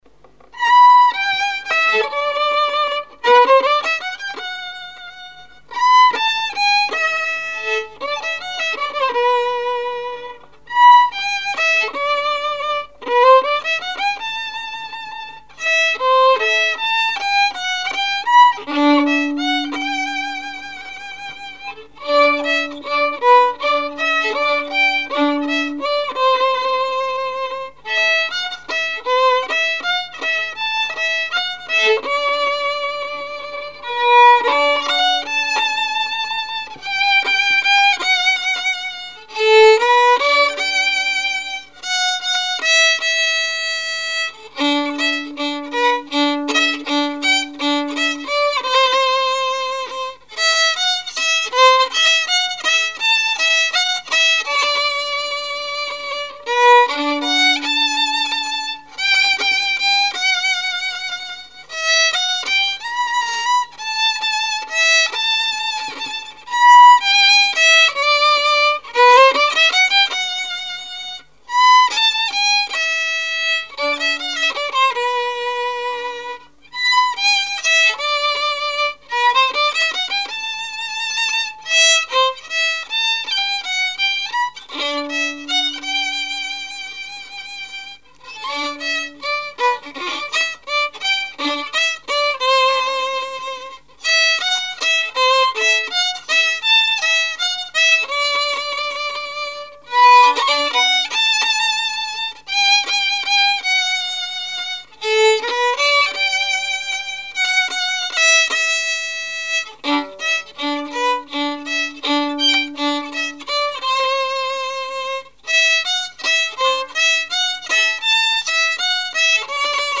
Mémoires et Patrimoines vivants - RaddO est une base de données d'archives iconographiques et sonores.
Valse
violoneux, violon,
Répertoire musical au violon
Pièce musicale inédite